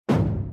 explosion.mp3